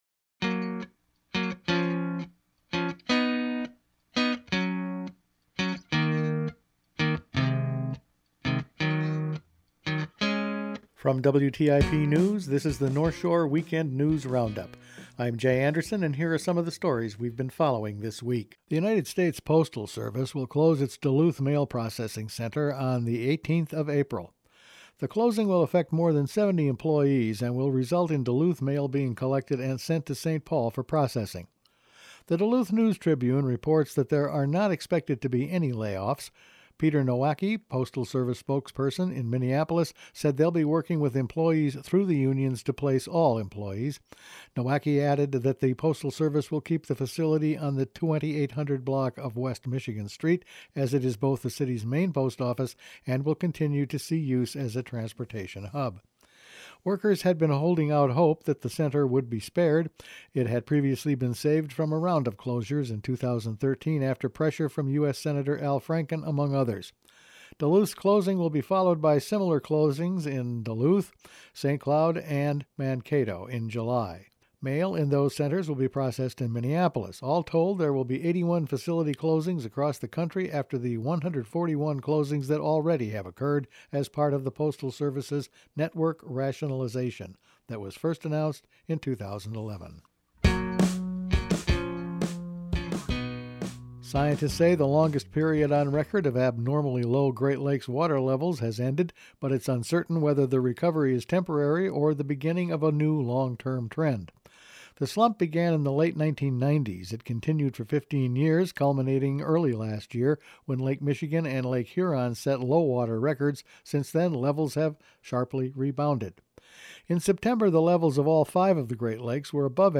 Weekend News Roundup for December 13